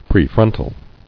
[pre·fron·tal]